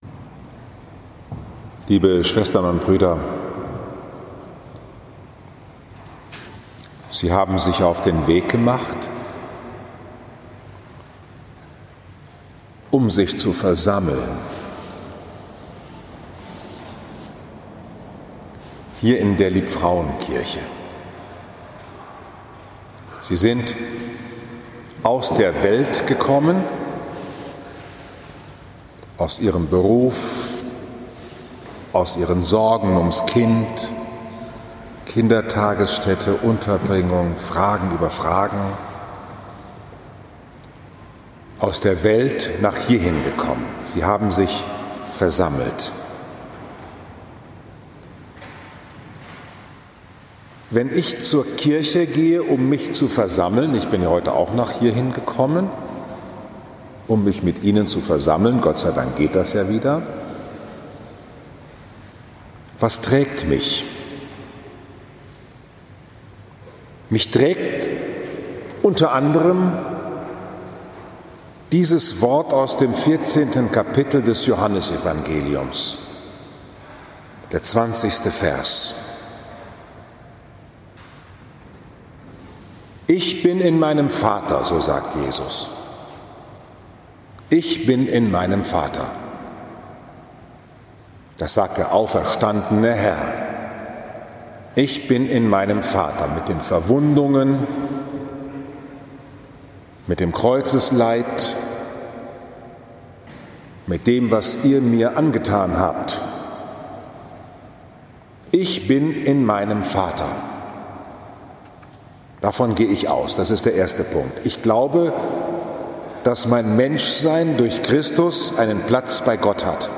Meditation zu Joh 14,20 mit anschl. Musik aus den Gesängen der
17. Mai 2020, 20 Uhr, Liebfrauenkirche Frankfurt am Main, 6. So.